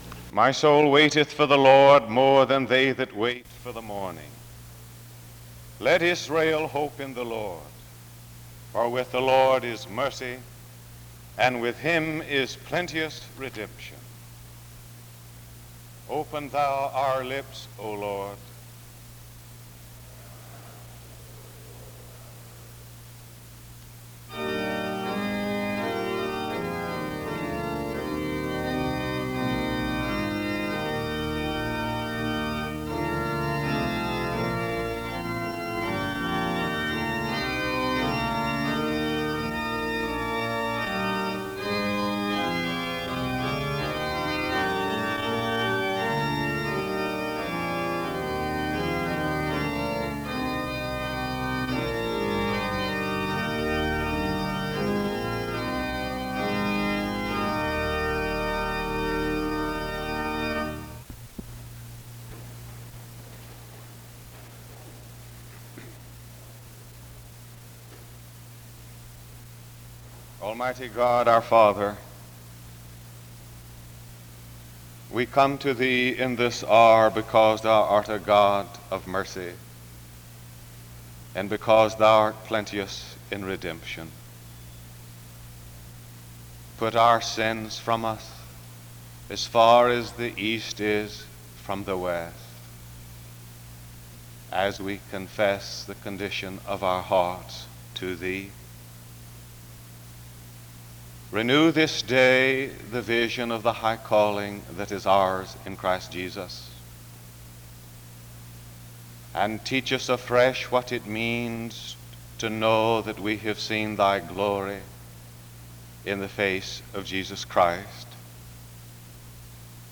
SEBTS Chapel
The service begins with prayer and music from 0:00-1:00. A prayer is offered from 1:09-3:32. A responsive reading and song takes place from 3:33-4:57.
Music plays from 18:08-19:48.